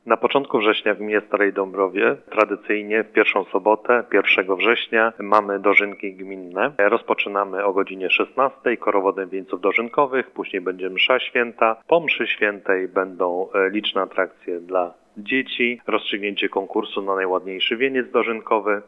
– mówi Mieczysław Włodarczyk, wójt gminy Stara Dąbrowa.